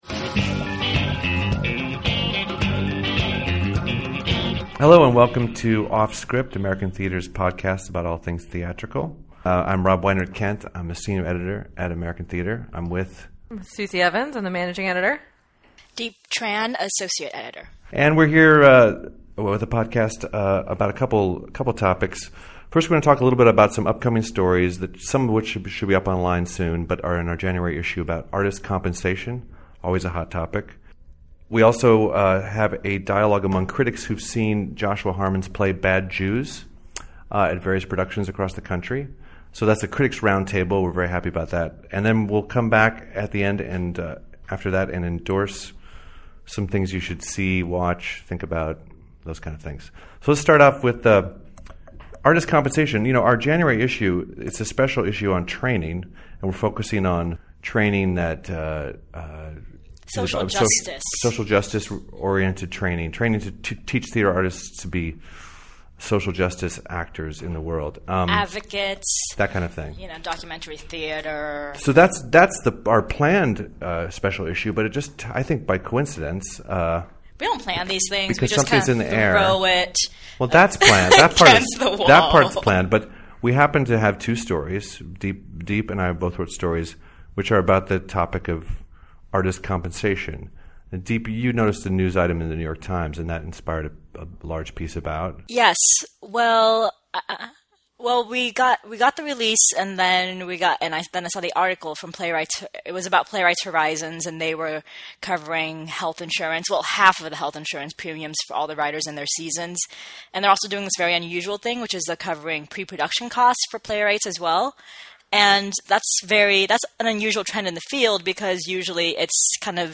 This week, American Theatre ‘s own podcast also talks a bit about artist compensation, and hosts a national critic’s roundtable about Joshua Harmon’s popular new play Bad Jews .